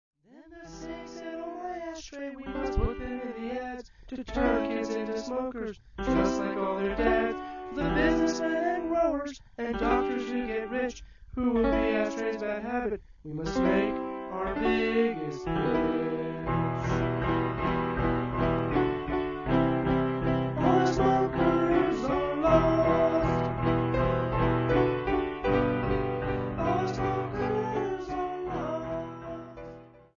I have no musical background and I apologize for my voice.
At least my grand piano has some talent.